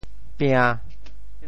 调: 低
国际音标 [pĩã]